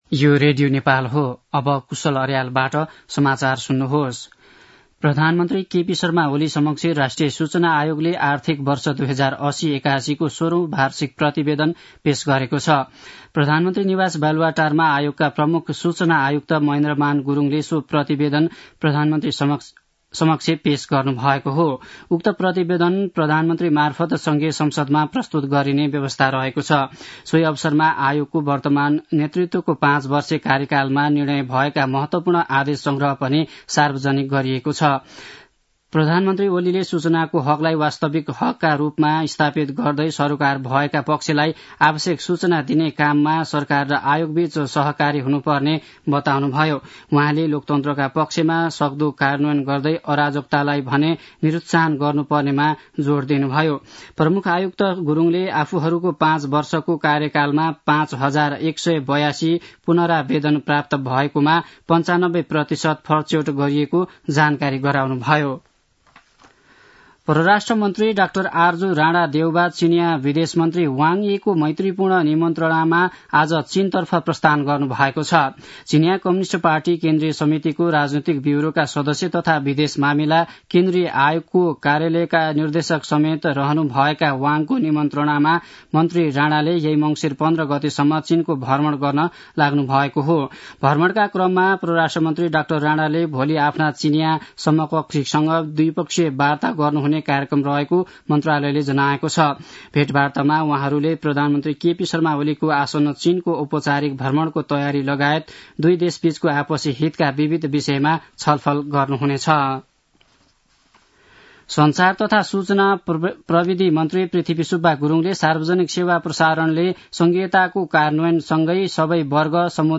दिउँसो १ बजेको नेपाली समाचार : १४ मंसिर , २०८१
1-pm-nepali-news-1-10.mp3